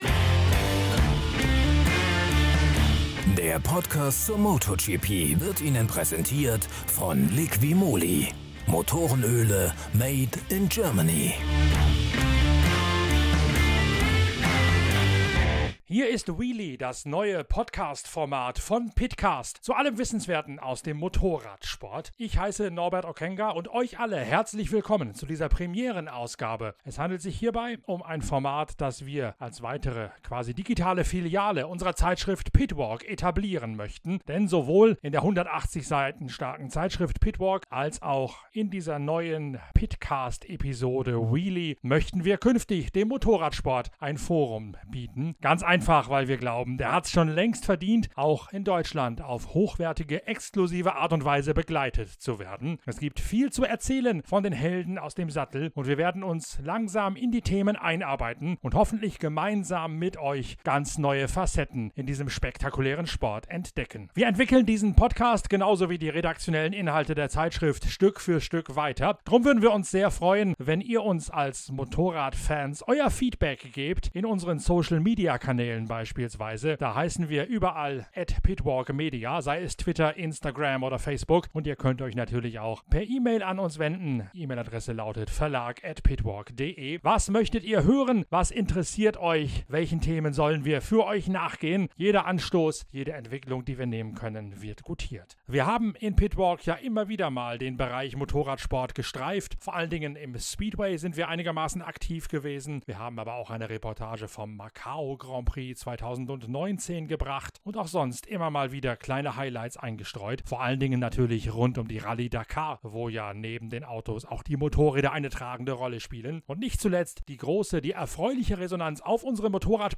Kurz, knackig, fundiert – und mit O-Tönen von Racern für Racer. In der ersten Folge hört Ihr eine Vorschau auf das MotoGP-Wochenende in Brünn.